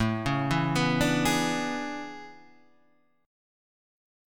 A Major 11th